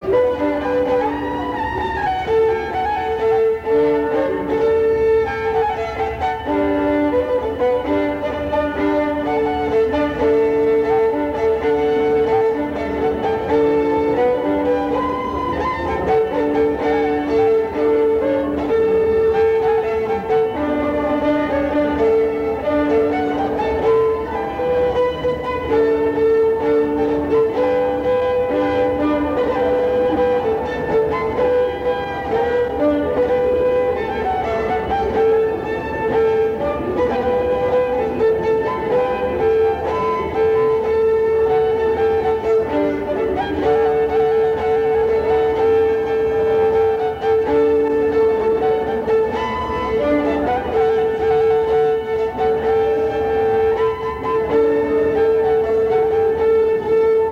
danse : polka
Genre strophique
Pièce musicale inédite